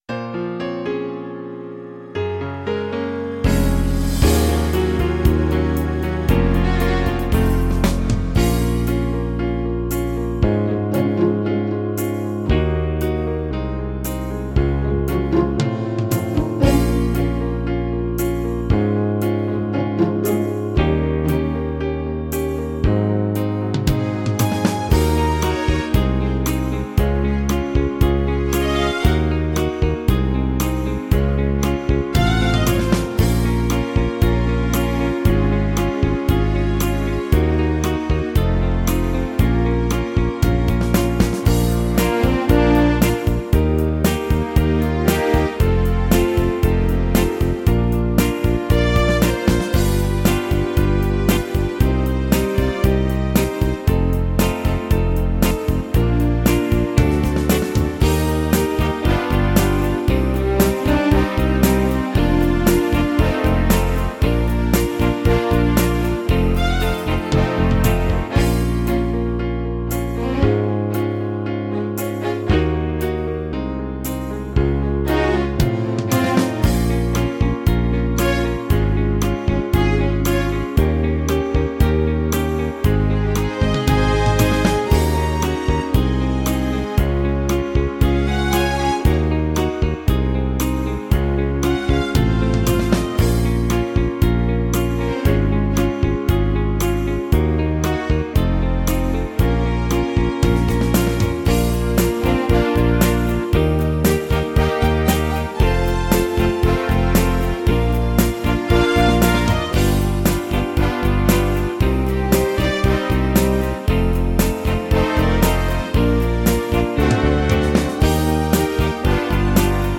hymn
Podkład muzyczny można pobrać